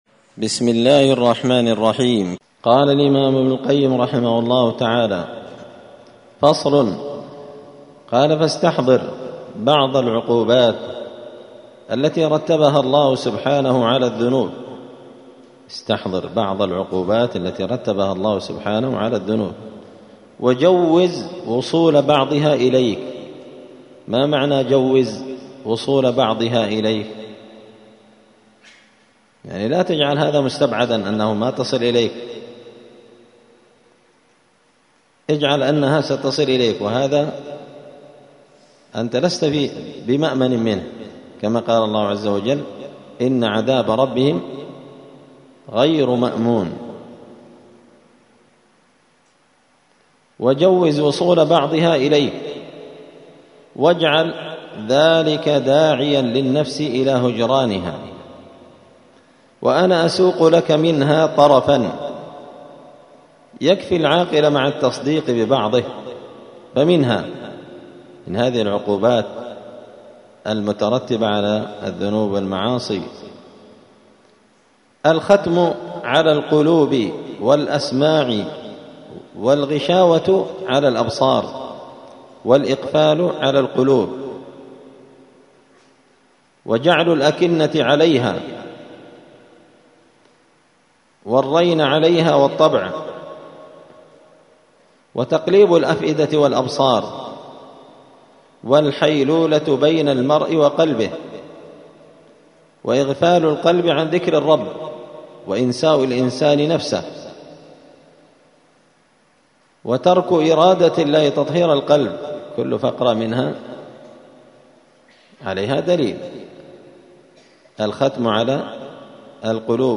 الجمعة 24 شوال 1445 هــــ | الداء والدواء للإمام ابن القيم رحمه الله، الدروس، دروس الآداب | شارك بتعليقك | 21 المشاهدات